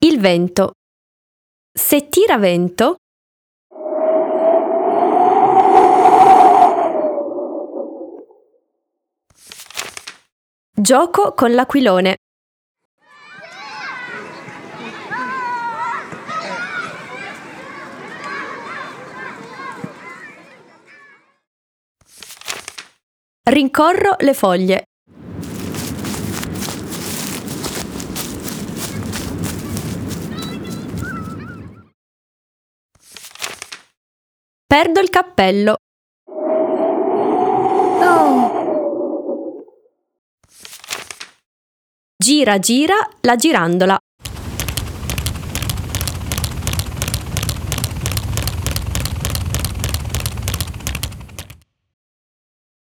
Booktrailer in stop-motion del libro in tessuto “Il vento”
vento.wav